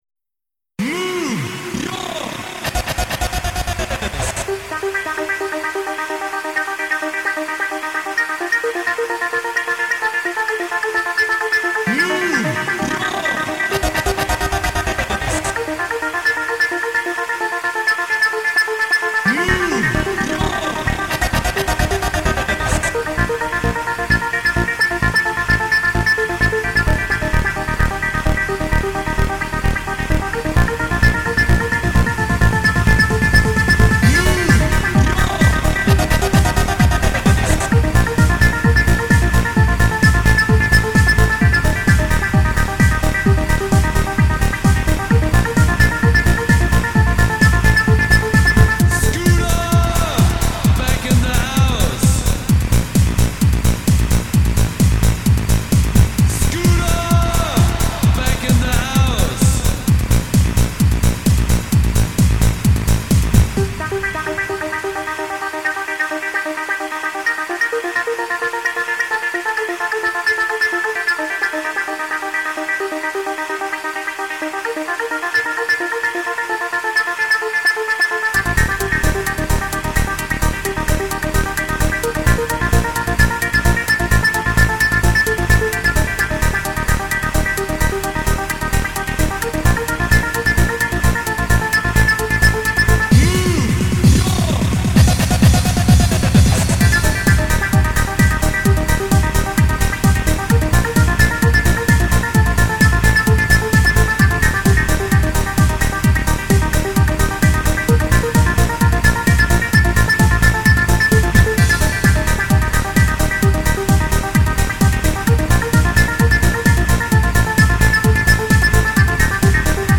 • Quality: 44kHz, Stereo